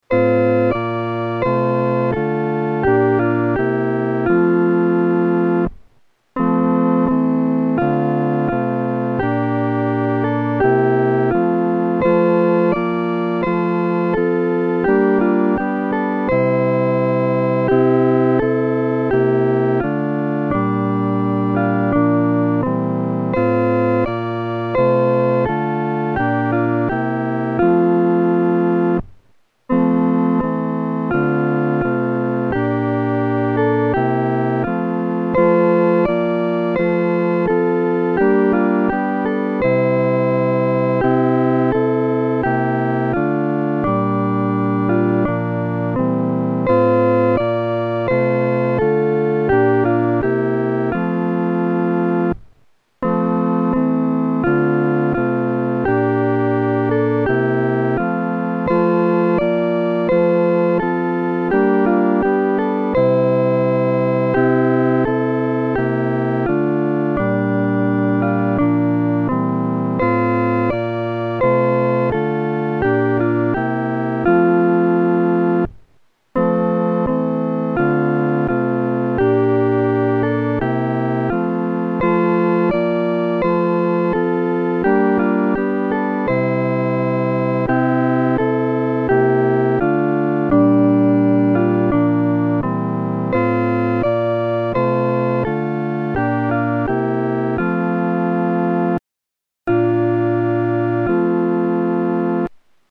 伴奏
四声
它的进行简单朴素，所表达的是对神真诚的依赖与信靠。